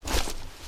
glowstick_draw.ogg